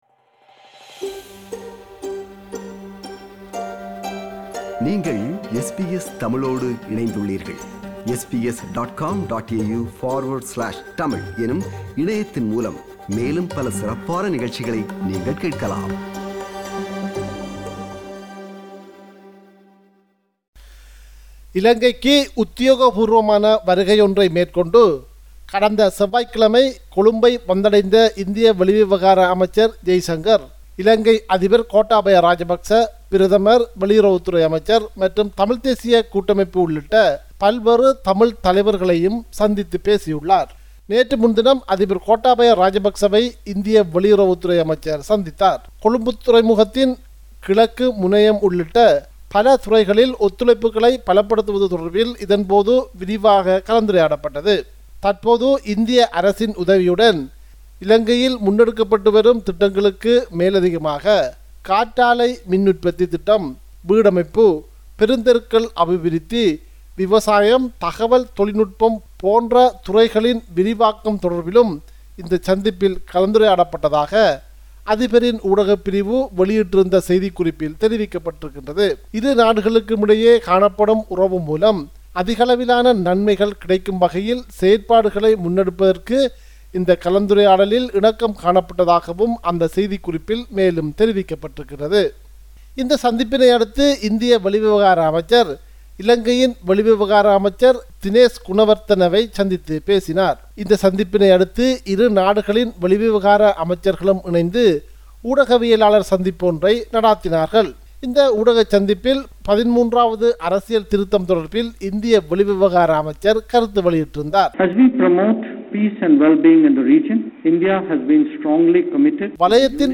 compiled a report focusing on major events/news in North & East